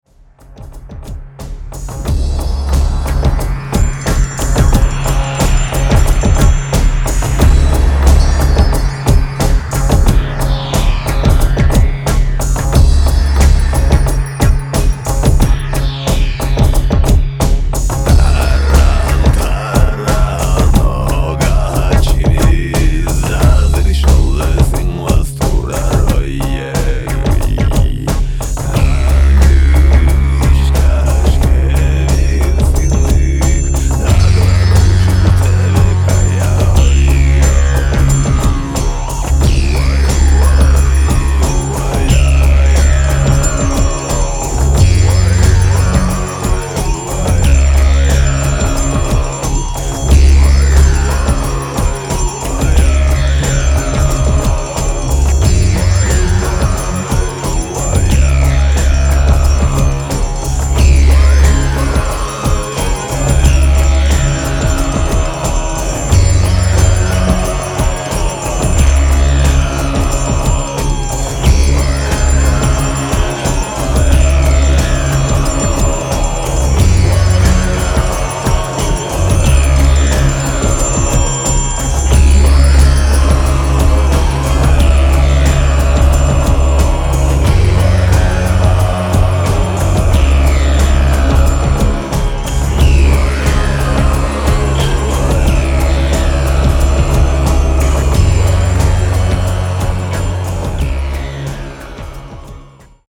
サウンドスケープ　　霊性　呪術　フォーク